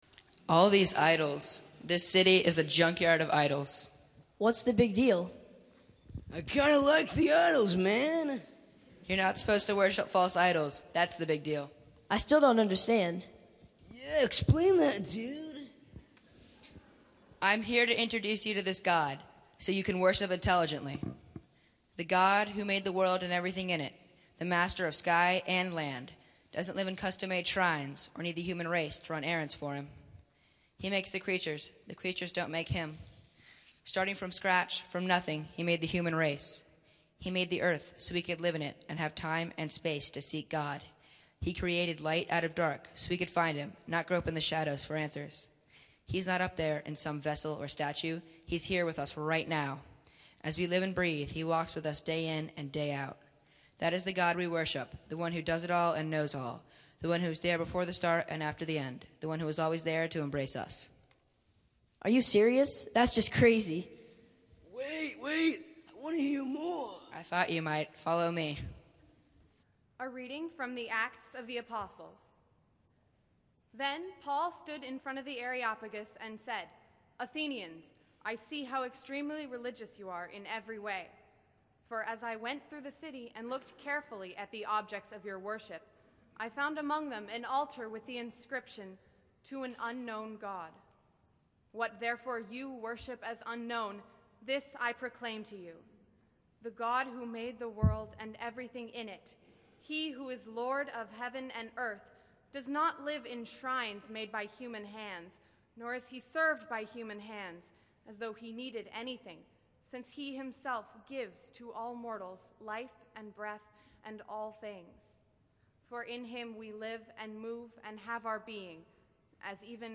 52012YouthSermon.mp3